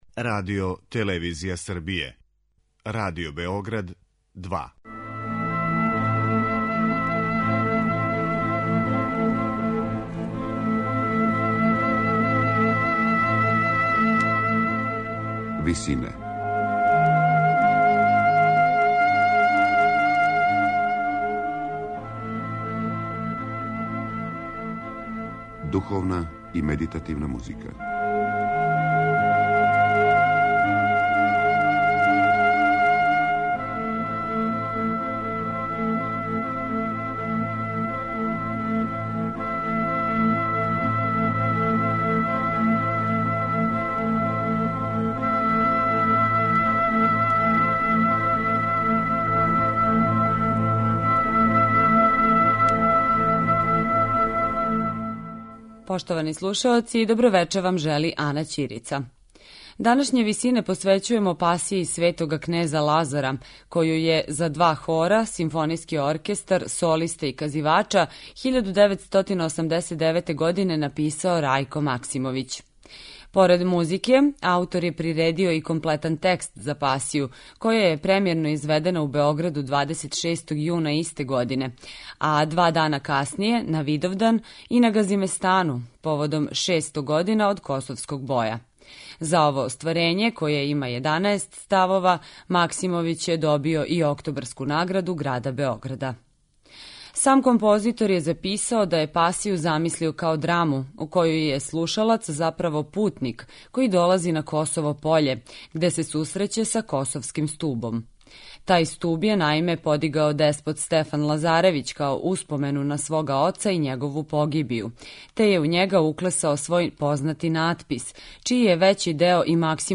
за два хора, симфонијски оркестар, солисте и казивача